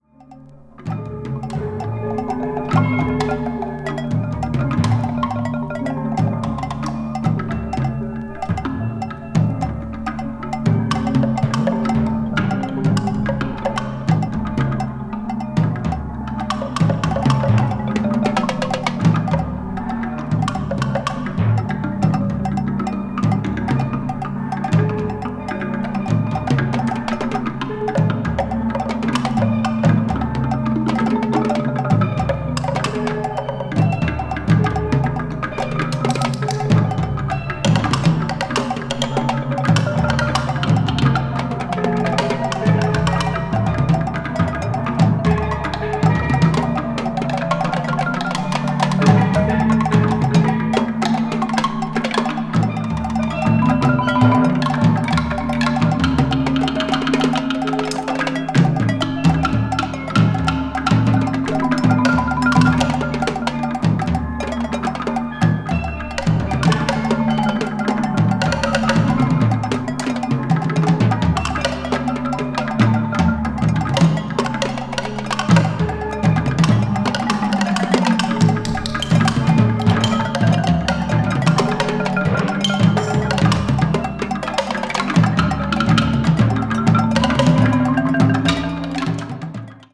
media : EX/EX(some slightly noises.)
The percussion on side B is also amazing.